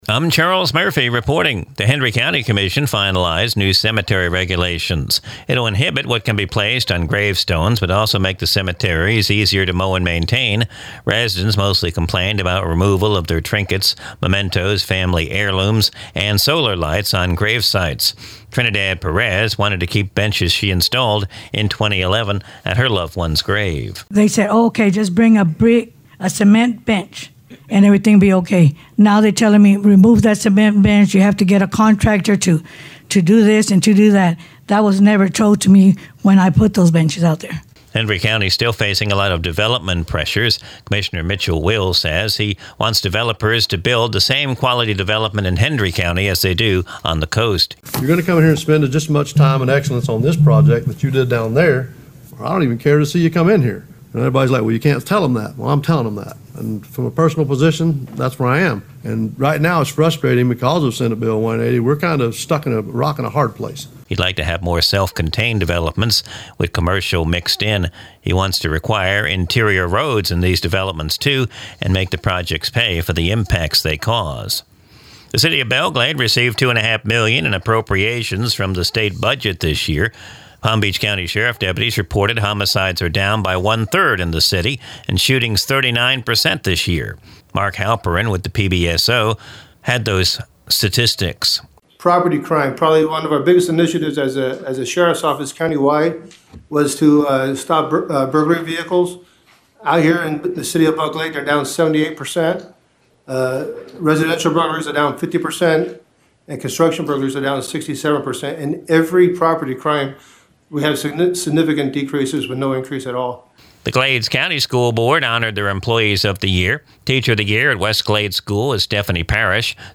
Recorded from the WAFC daily newscast (Glades Media).